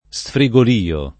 sfrigolio [ S fri g ol & o ] s. m.